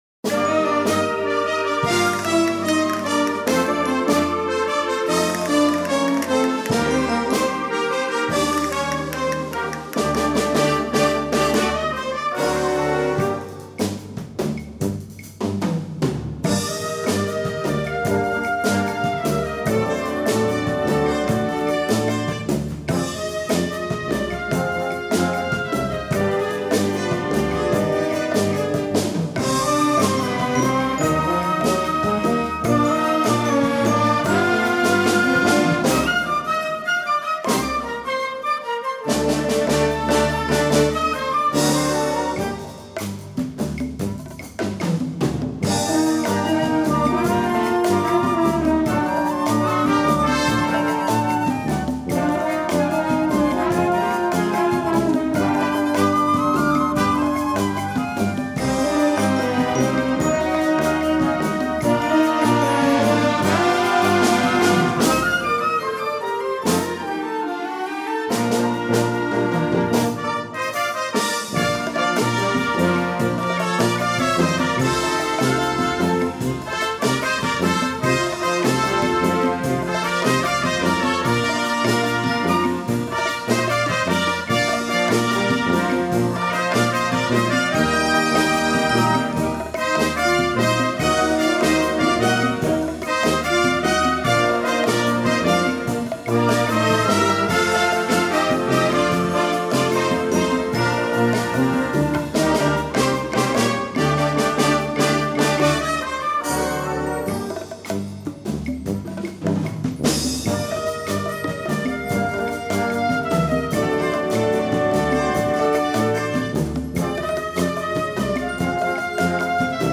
Gattung: Rumba
Besetzung: Blasorchester